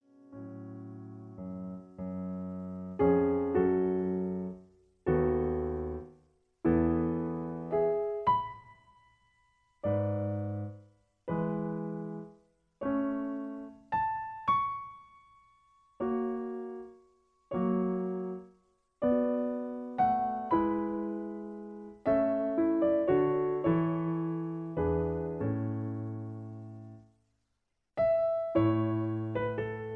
In F sharp. Piano Accompaniment